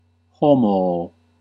Ääntäminen
Synonyymit man human being sort Ääntäminen US UK Tuntematon aksentti: IPA : /ˈpɝ.sən/ IPA : /ˈpɜː(ɹ)sən/ RP : IPA : /ˈpɜː.sən/ Lyhenteet ja supistumat pers.